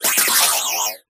silicobra_ambient.ogg